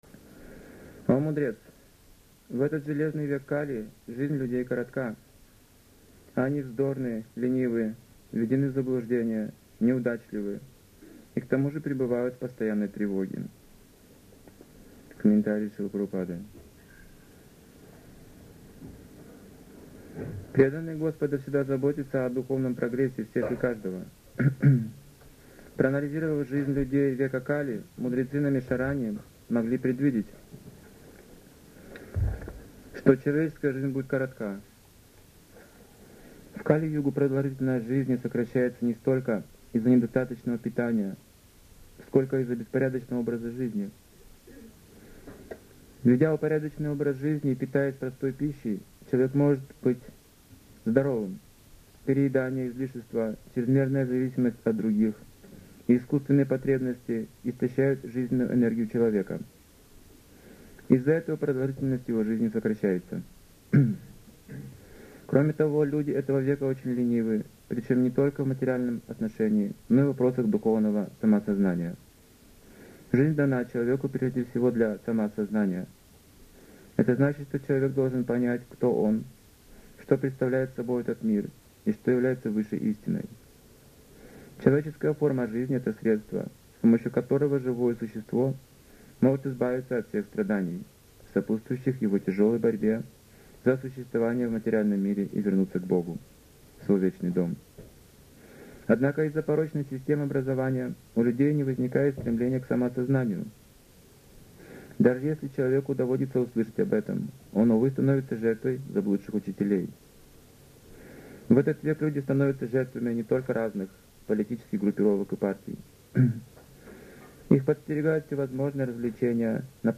Темы, затронутые в лекции: